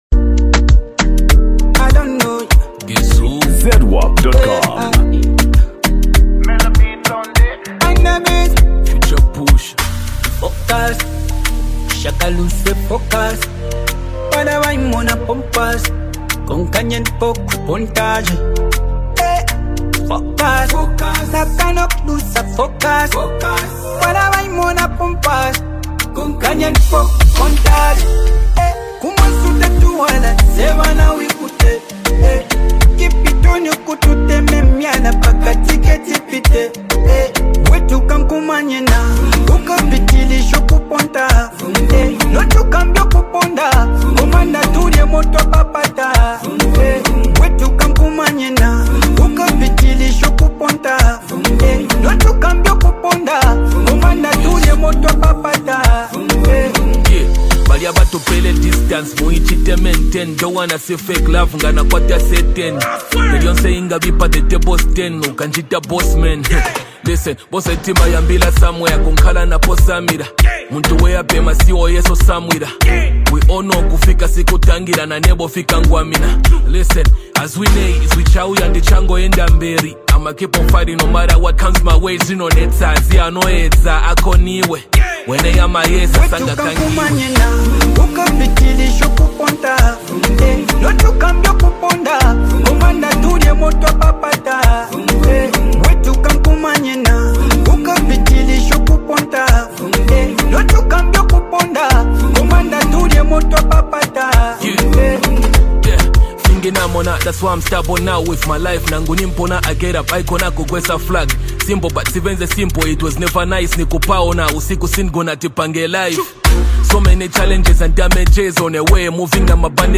Latest Zambia Afro-Beats Single (2026)
Genre: Afro-Beats
blending catchy melodies with meaningful songwriting.